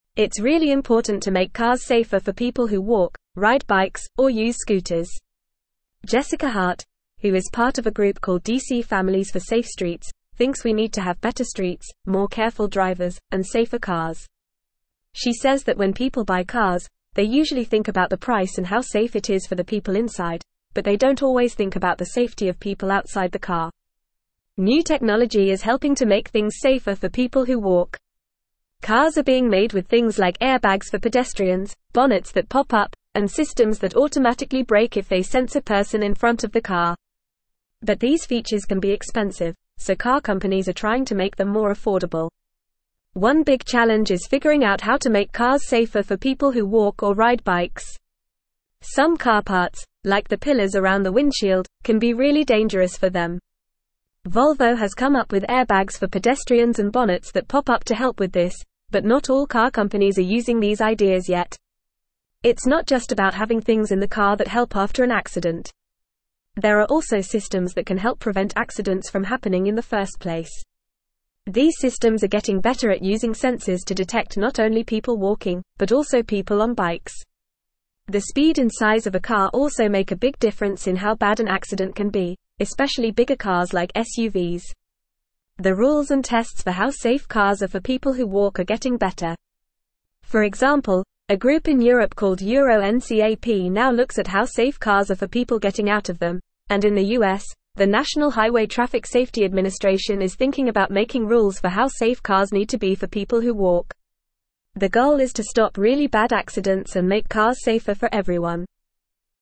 Fast
English-Newsroom-Upper-Intermediate-FAST-Reading-Advocating-for-Safer-Streets-Protecting-Vulnerable-Road-Users.mp3